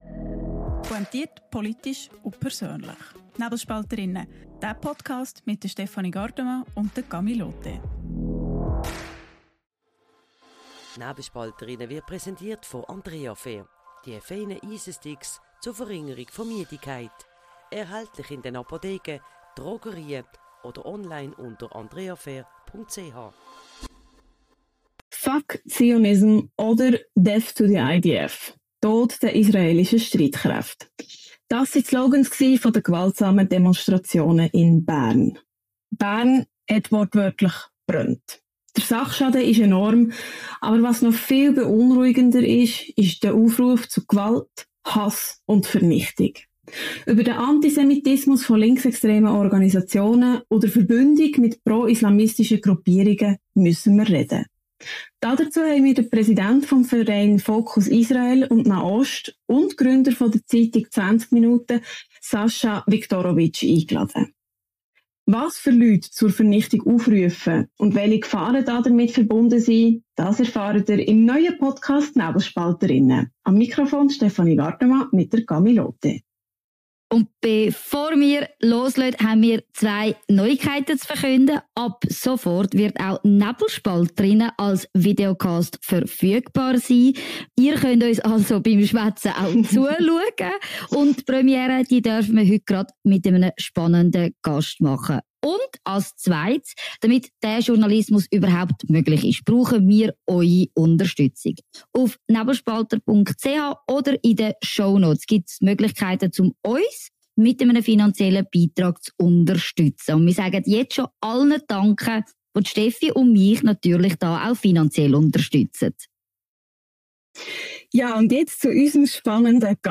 Im Gespräch spricht er über den neuen Antisemitismus, über die blinden Flecken der Linken und über die Untätigkeit der UNO.